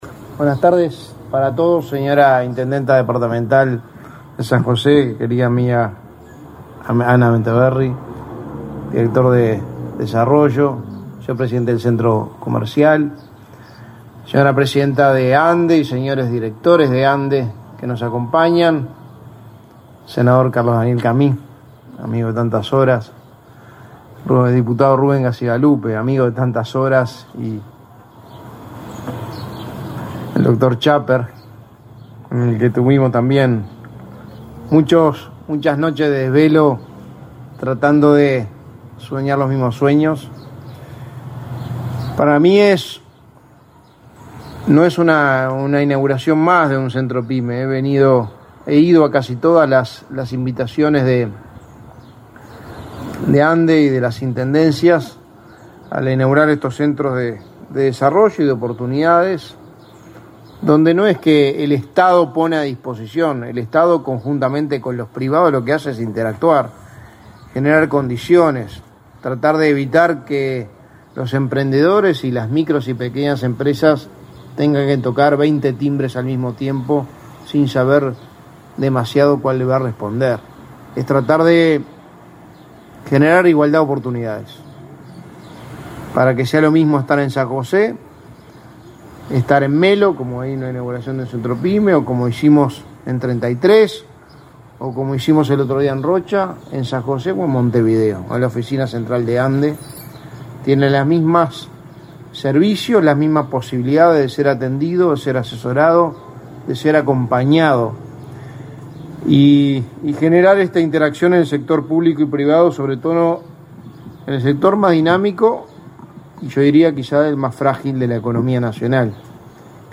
Conferencia de prensa por la inauguración del Centro Pyme en San José
Conferencia de prensa por la inauguración del Centro Pyme en San José 09/12/2021 Compartir Facebook X Copiar enlace WhatsApp LinkedIn Con la presencia del secretario de la Presidencia, Álvaro Delgado, la Agencia Nacional de Desarrollo (ANDE) inauguró, este 9 de diciembre, el Centro Pyme en San José. Participó la presidenta de ANDE, Carmen Sánchez.